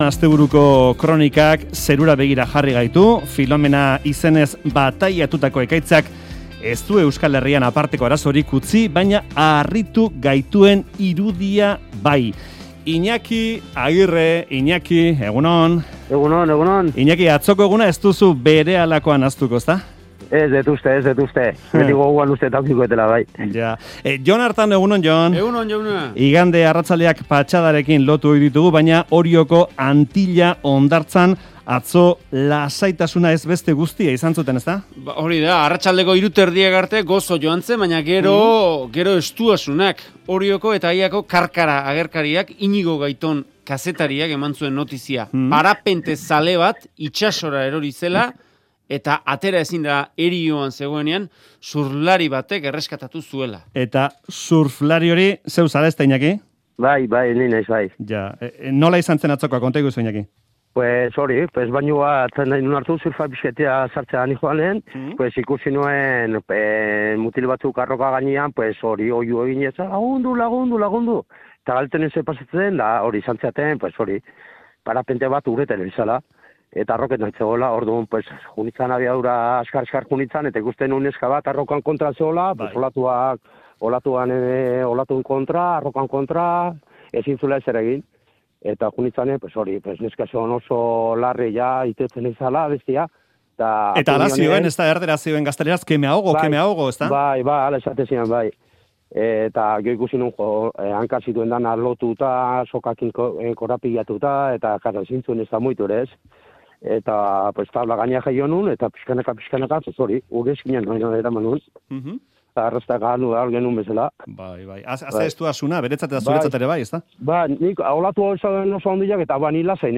Audioa: Orion itsasora eroritako parapentista erreskatatu duen surflaria